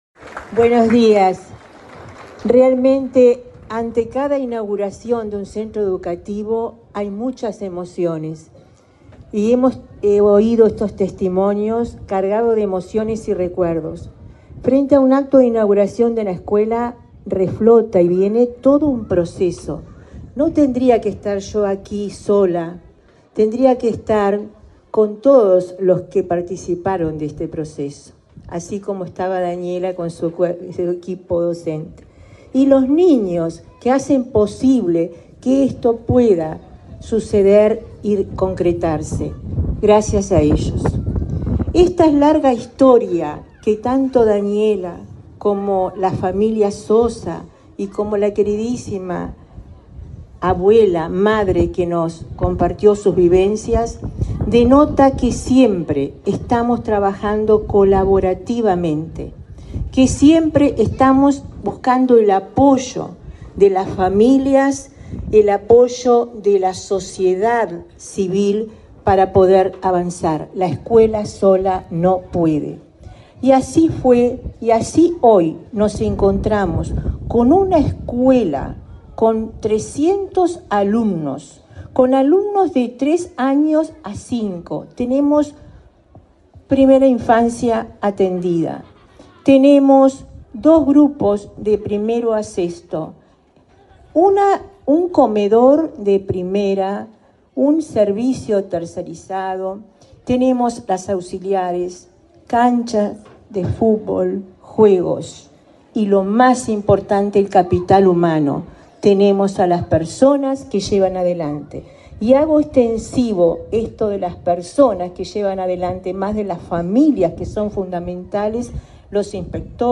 Palabras de autoridades de la ANEP
Palabras de autoridades de la ANEP 06/09/2024 Compartir Facebook X Copiar enlace WhatsApp LinkedIn La directora general de Primaria. Olga de las Heras, y la presidenta de la Administración Nacional de Educación Pública (ANEP), Virginia Cáceres, participaron en la inauguración del edificio de la escuela n.° 224, José Roger Balet, en el barrio Abayubá de Montevideo.